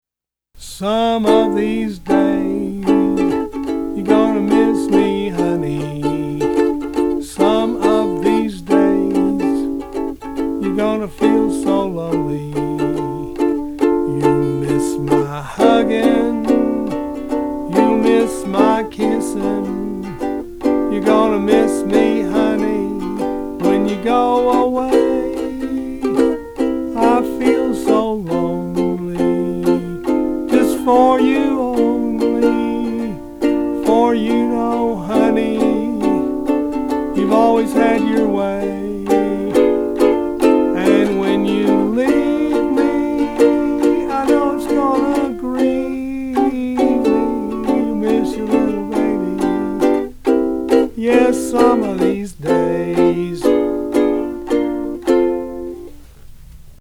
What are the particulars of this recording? Please ignore any sour notes.